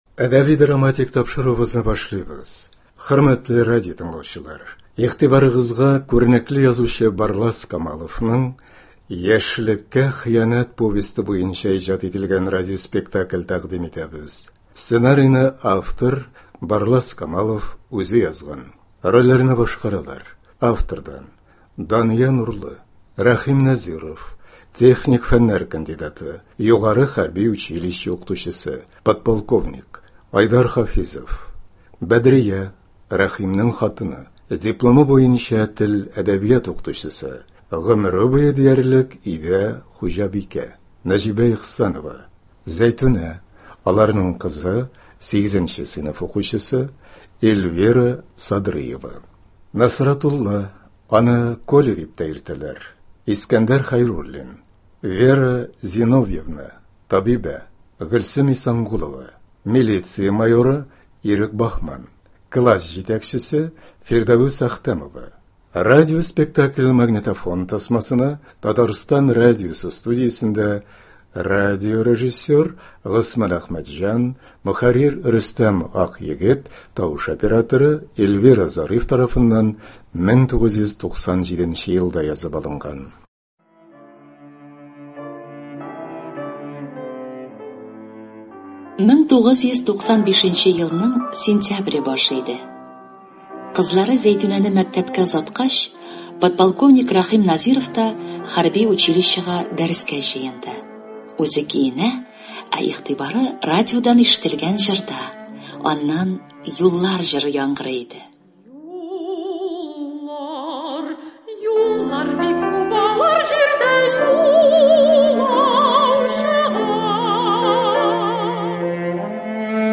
Радиоспектакль (23.09.23)